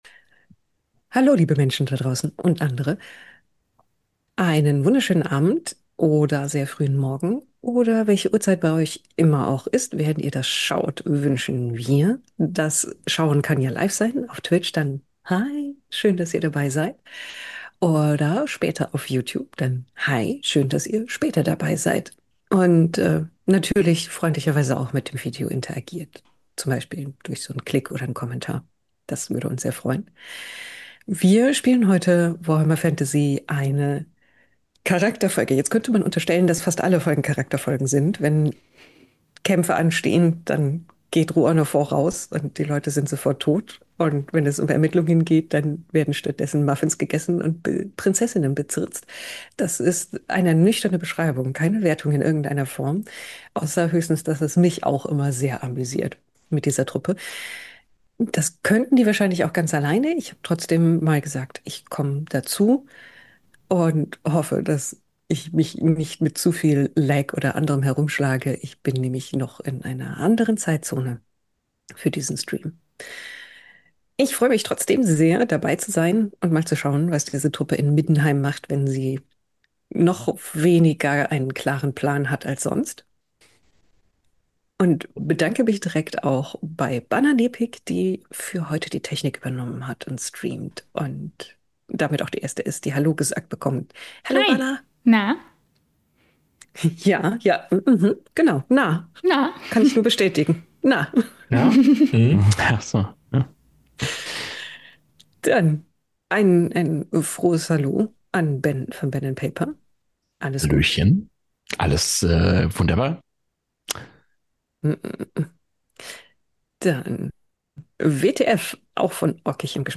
Ein Pen and Paper Actual Play von Orkenspalter TV Die 5.